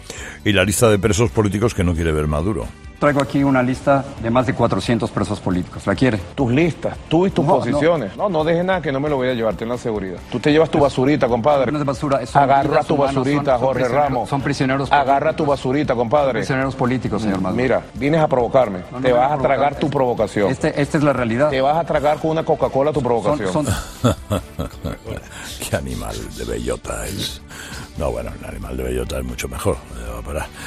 En los sonidos del día, Carlos Herrera ha aprovechado un corte de esta entrevista para dirigirse al presidente de Venezuela. Con ironía y entre risas, el comunicador ha dicho: “Qué animal de bellota es. Bueno, un animal de bellota es mucho mejor, dónde vas a parar”.